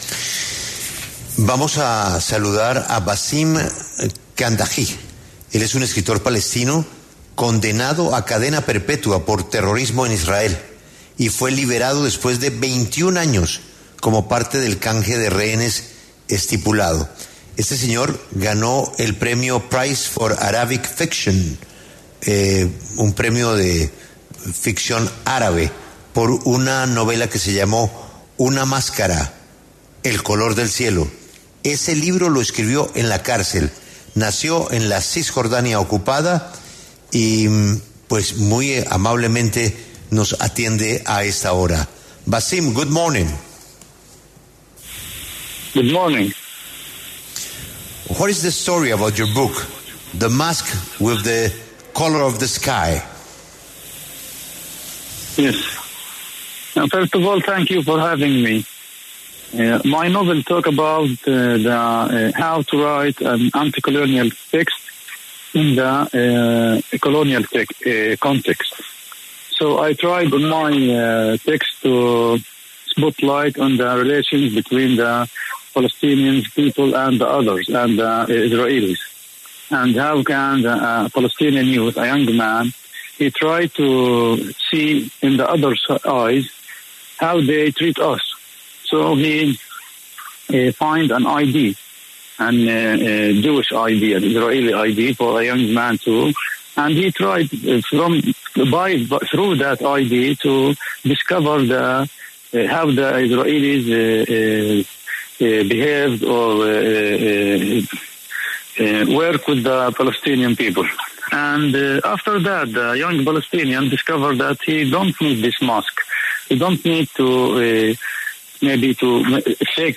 Basim Khandaqji, escritor palestino quien fue condenado a cadena perpetua en Israel, conversó con La W tras su reciente liberación en medio del canje logrado en el cese al fuego.